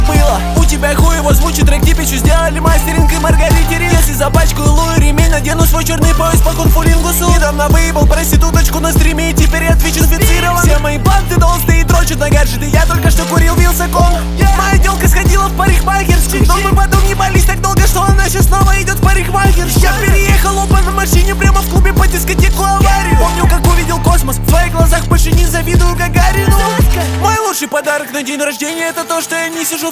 Жанр: Рэп и хип-хоп / Иностранный рэп и хип-хоп / Русские